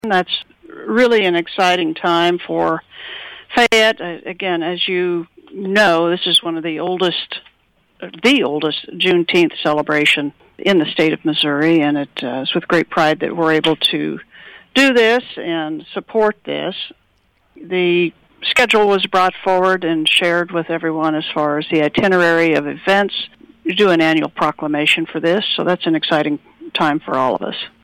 At last week’s council meeting, Mayor Greg Stidham and the board of aldermen presented a proclamation recognizing the 25th annual celebration of Juneteenth in the city.